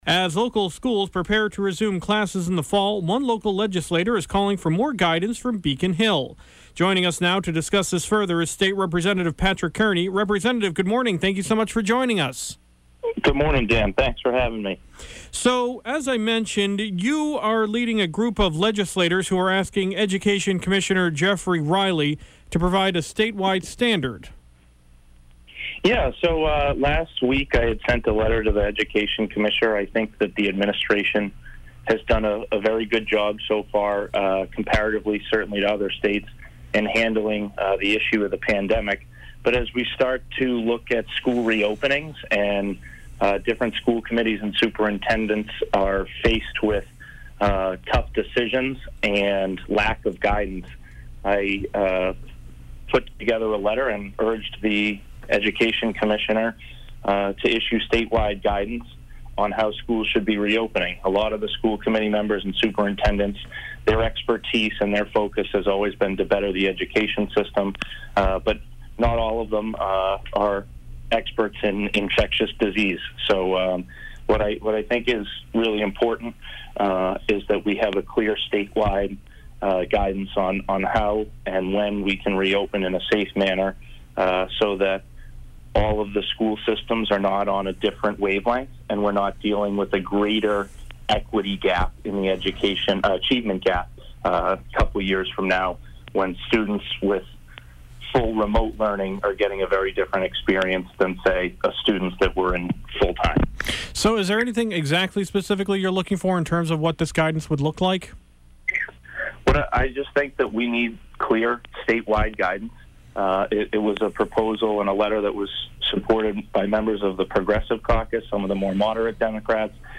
speaks with State Representative Patrick Kearney regarding his request for a statewide plan on school reopening.